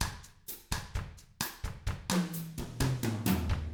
129BOSSAF2-L.wav